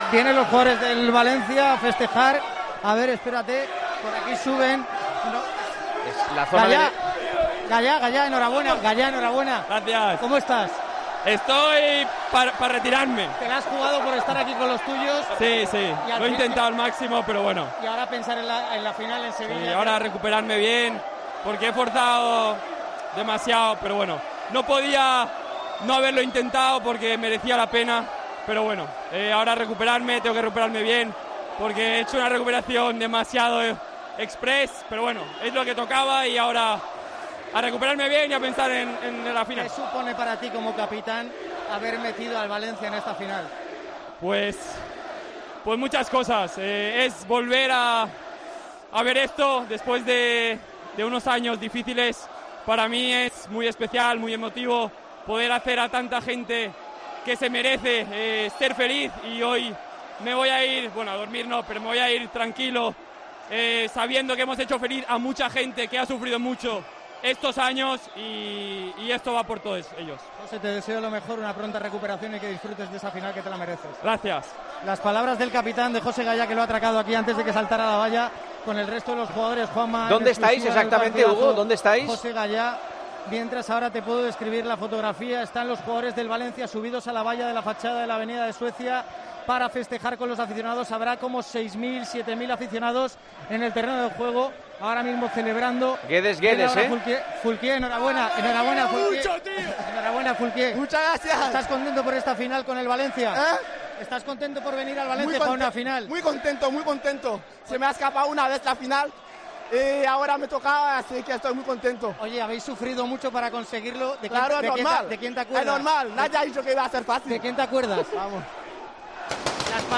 La alegría de Gayá, Foulquier y Guedes en el micrófono de COPE por clasificarse para la final de Copa